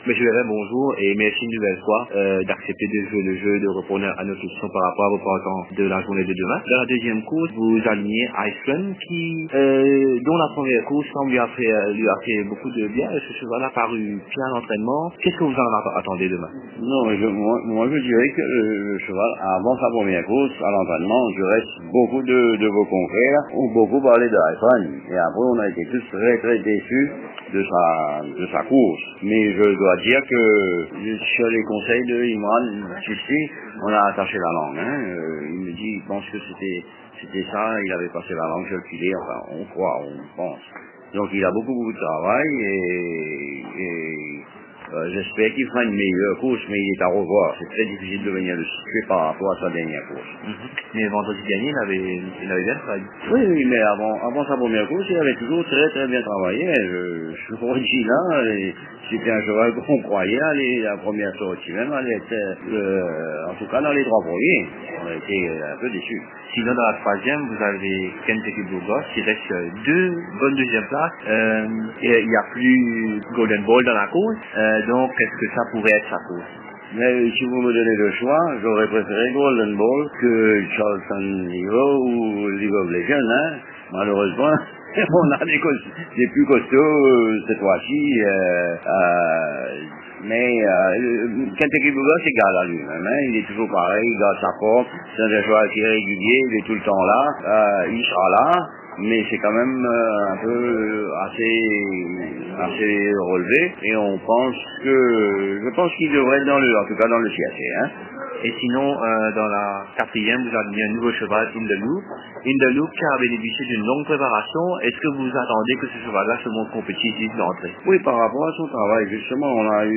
Dans l'entretien qui suit